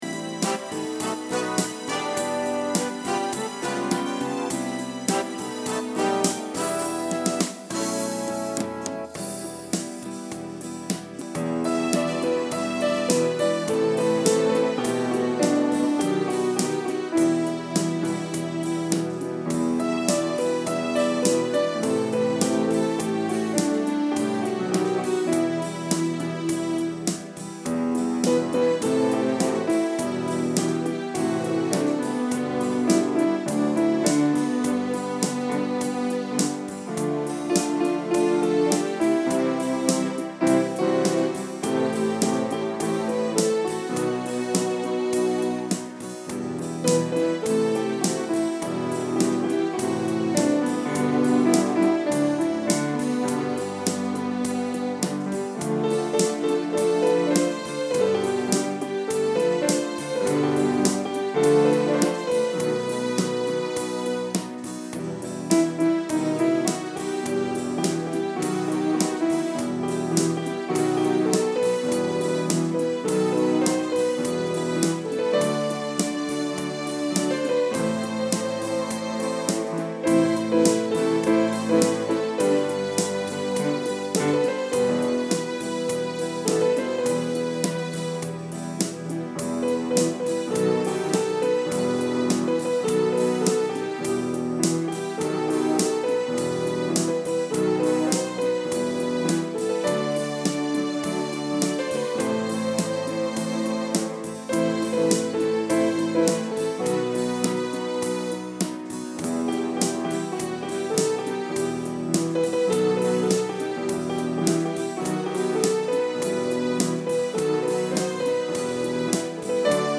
the Clavinova Version  Musical Critique
• Clarity without sterility
• Elegance without excess